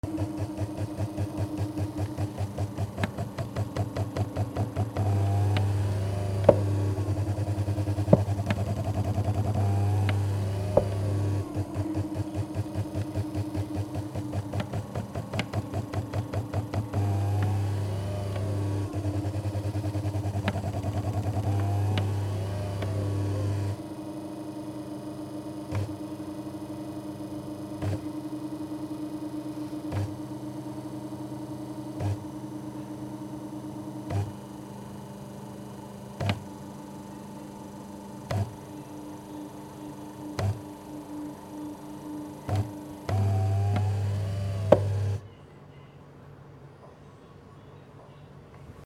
マッサージ機 モーター音(もみ＋振動)